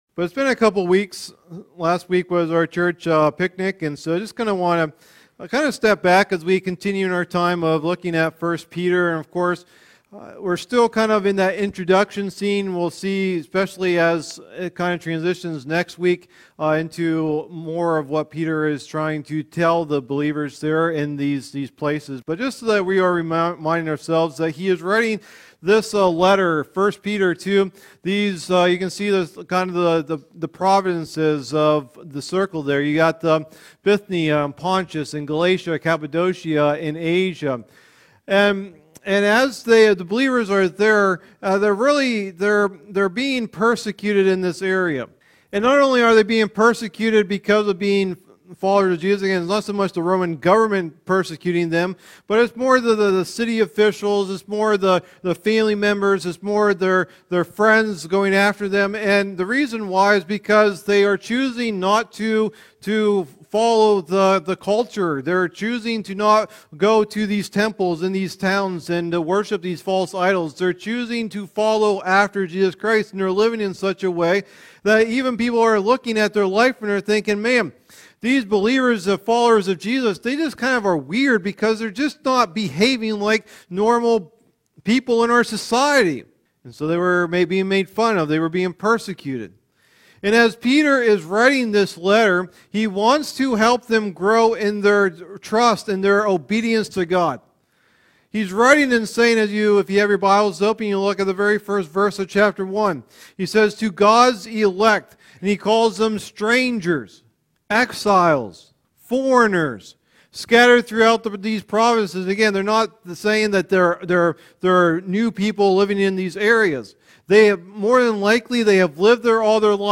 Message #3 in the "1 Peter" teaching series